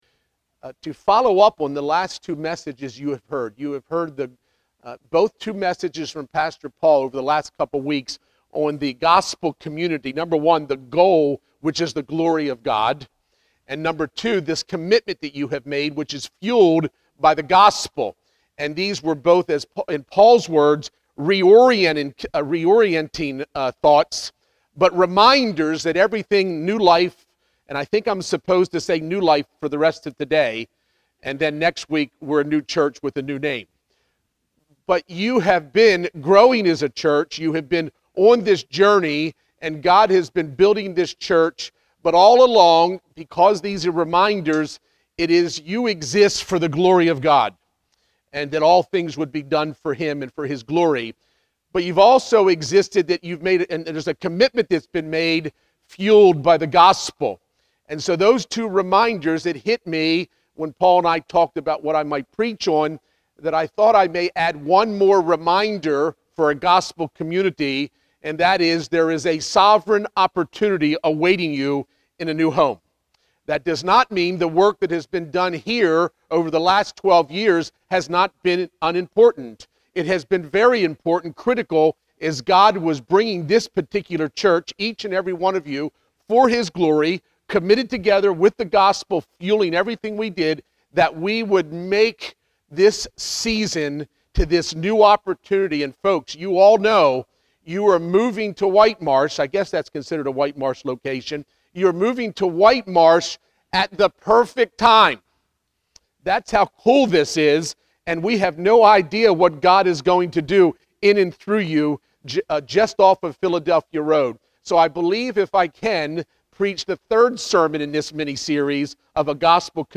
Sermons – Redeemer Community Church